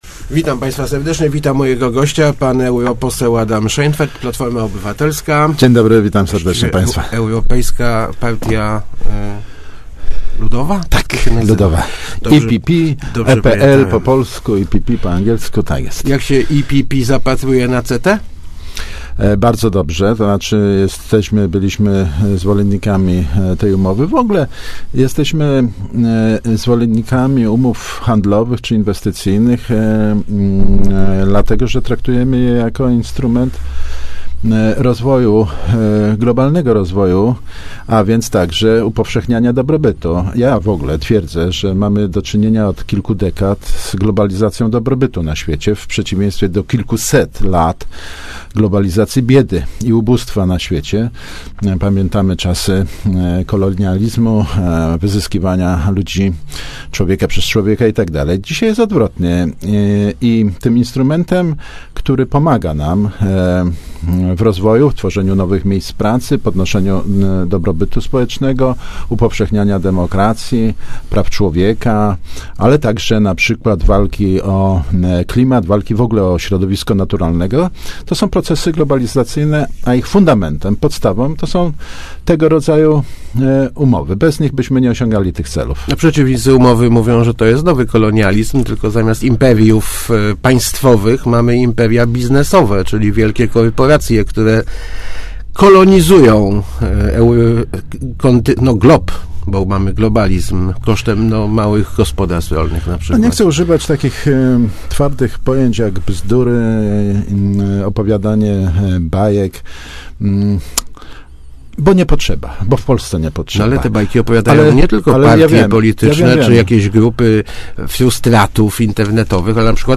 Takie umowy s� fundamentem upowszechniania dobrobytu – mówi� w Radiu Elka europose� Adam Szejnfeld, komentuj�c podpisanie umowy CETA. Zdaniem by�ego wiceministra gospodarki, Unii Europejskiej nie grozi zalew modyfikowanej �ywno�ci, bo CETA zabezpiecza zarówno kraje Unii, jak i Kanad� przed produktami niespe�niaj�cymi norm obowi�zuj�cych na danym rynku.